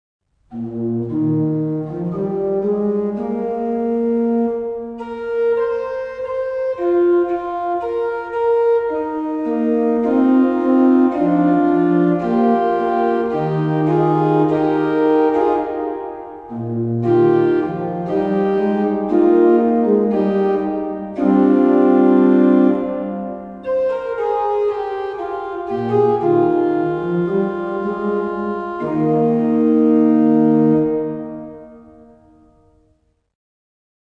Orgelvorspiele
Hier finden Sie einige Orgelvorspiele zu Liedern aus dem Gotteslob.